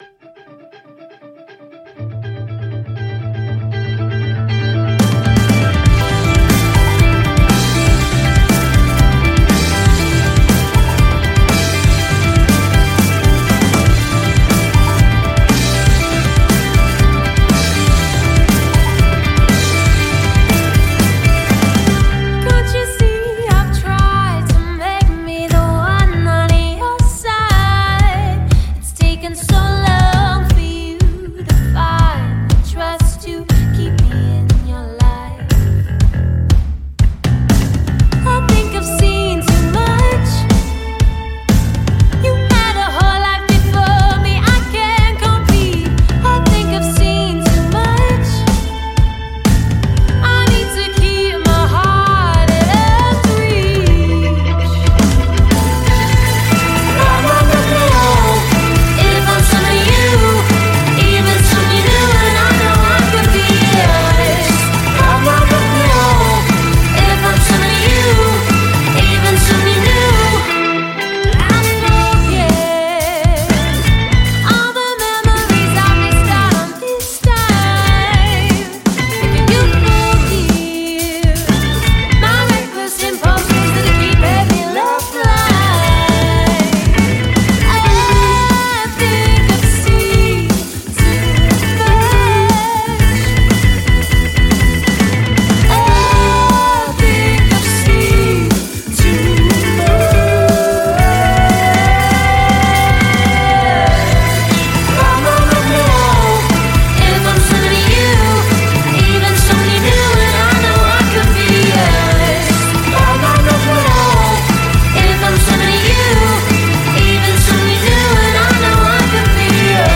Combining left field experimentation with pop sensibilities
alt. pop